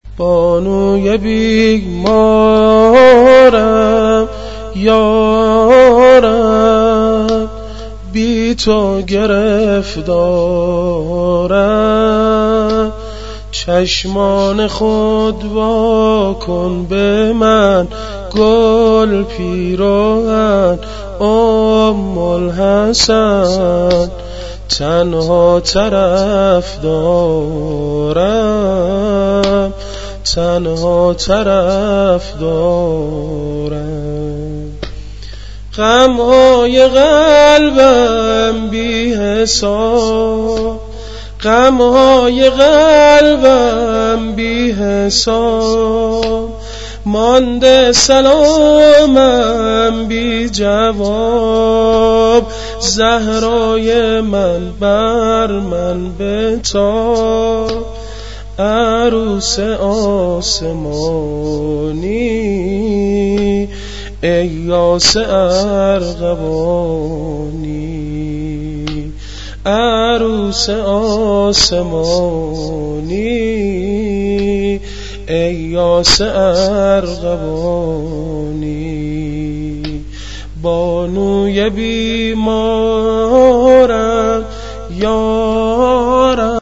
واحد ، زمزمه